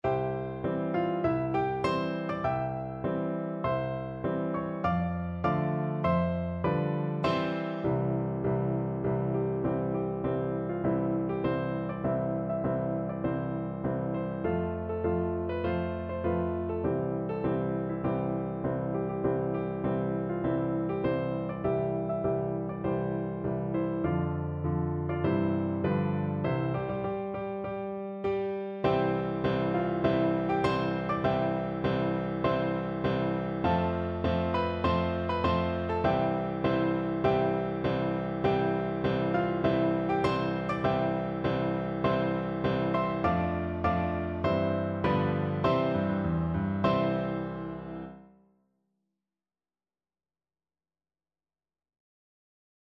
Free Sheet music for Piano Four Hands (Piano Duet)
March
4/4 (View more 4/4 Music)
Piano Duet  (View more Intermediate Piano Duet Music)
Classical (View more Classical Piano Duet Music)